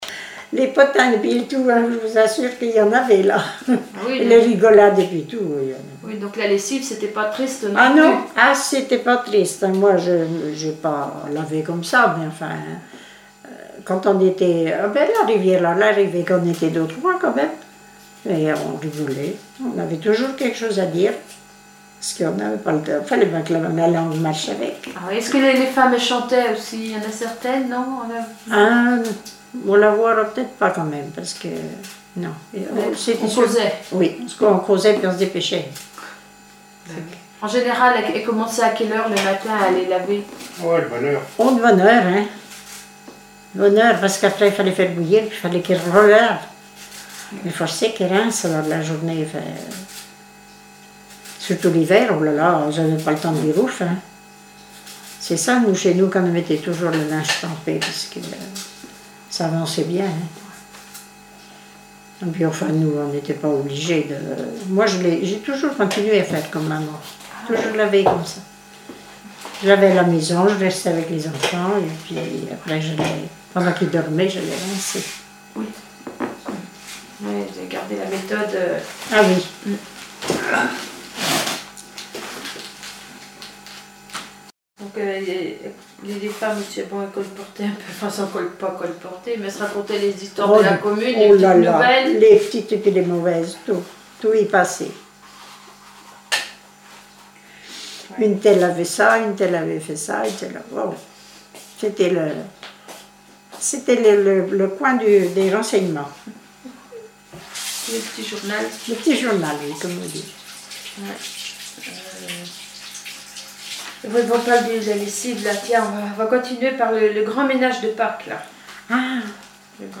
Témoignages sur les tâches ménagères
Catégorie Témoignage